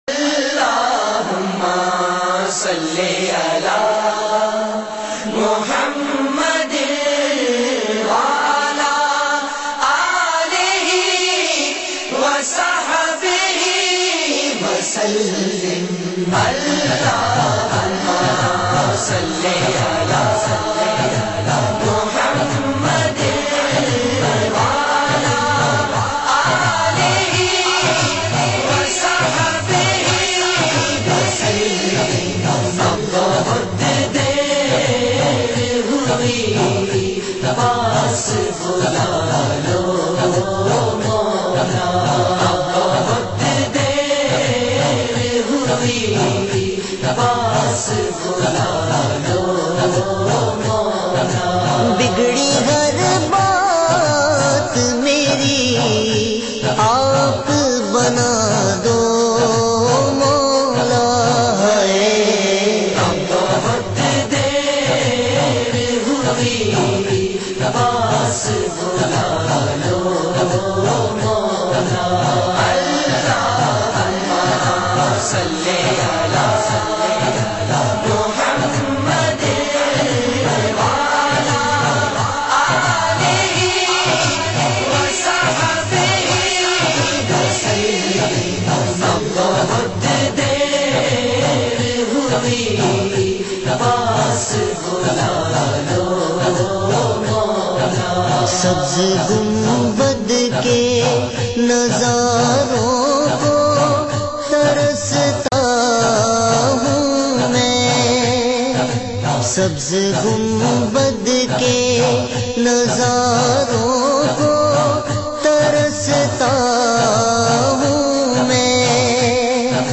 Heart Touching Naat
in a Heart-Touching Voice